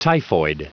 Prononciation du mot typhoid en anglais (fichier audio)
Prononciation du mot : typhoid